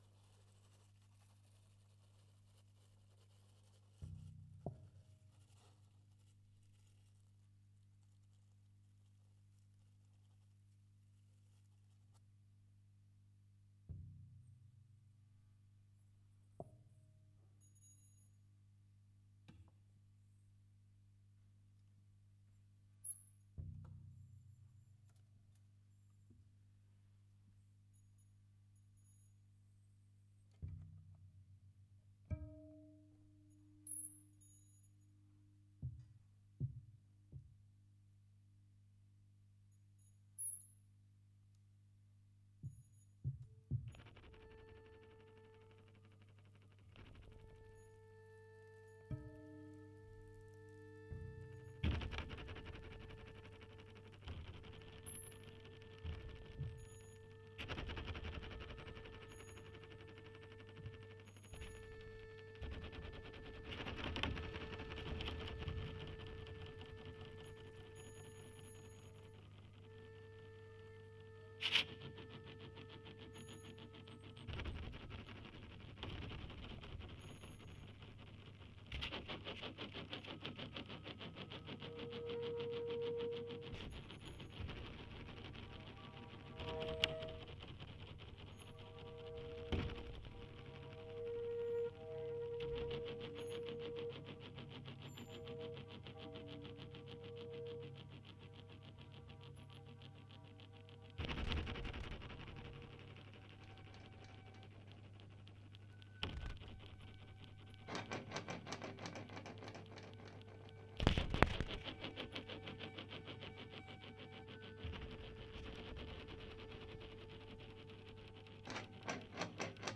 The Iberian trio
a distinct language of tension, scraped metallic dynamics